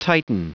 Prononciation du mot tighten en anglais (fichier audio)
tighten.wav